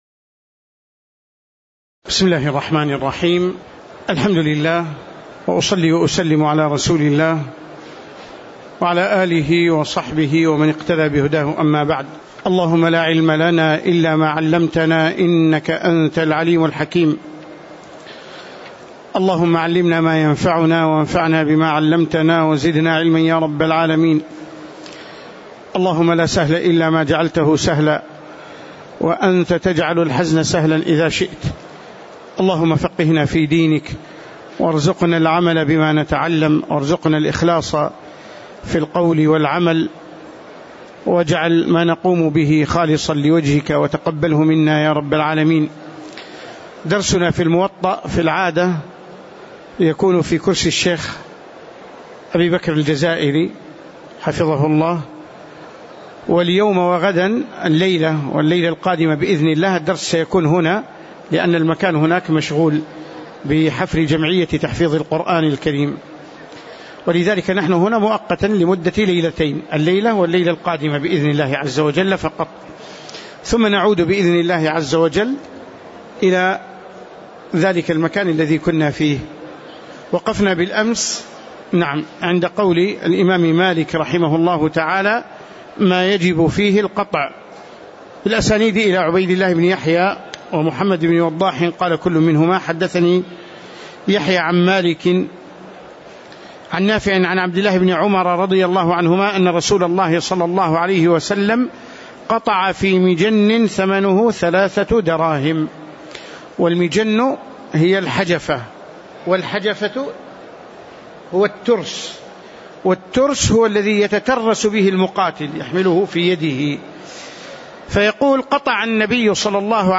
تاريخ النشر ٨ رمضان ١٤٣٧ هـ المكان: المسجد النبوي الشيخ